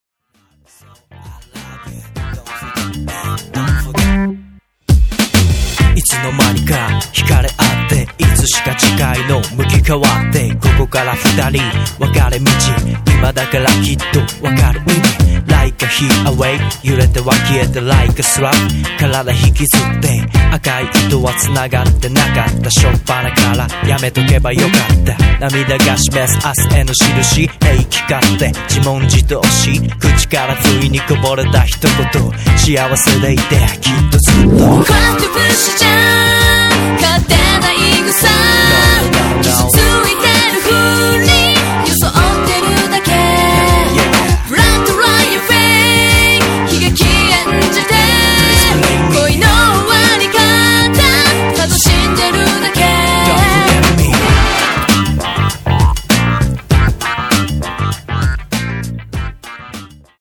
”スタイリッシュポップスユニット”